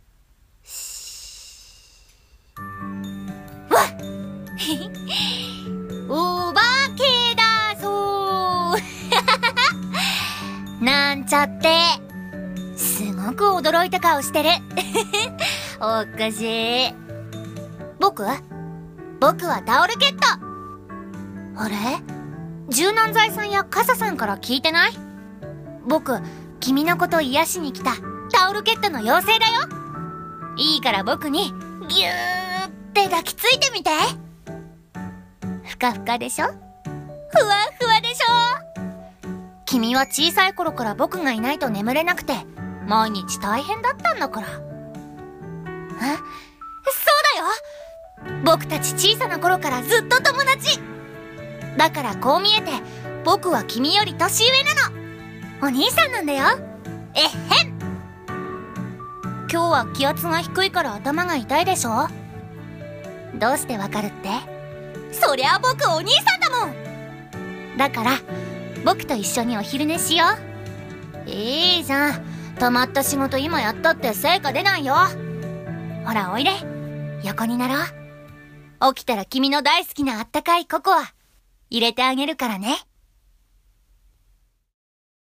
一人声劇